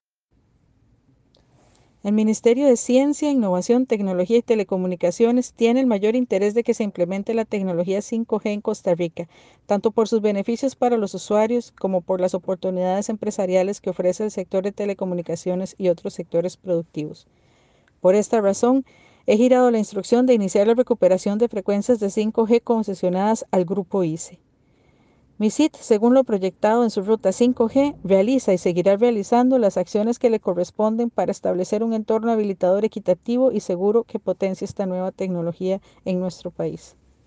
Declaraciones de la ministra Paola Vega Castillo sobre el inicio de recuperación de frecuencias de 5G